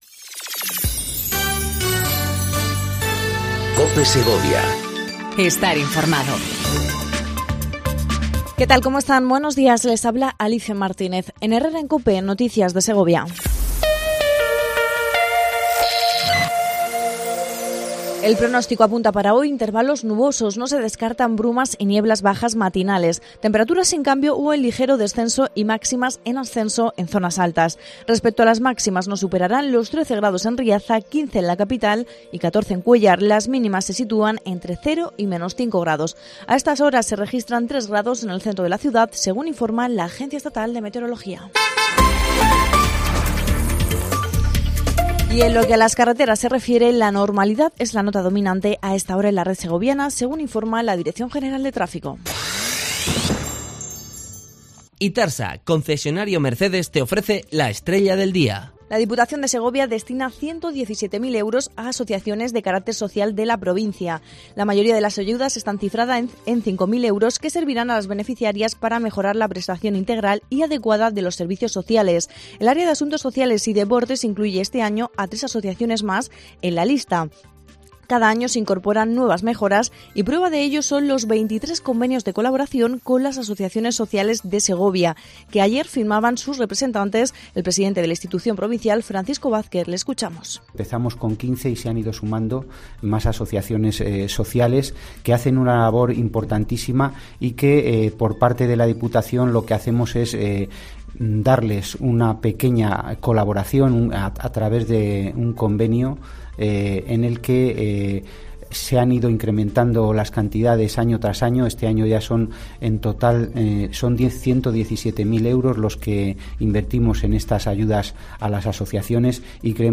INFORMATIVO 08:25 COPE SEGOVIA 08/02/19
AUDIO: Segundo informativo local en cope segovia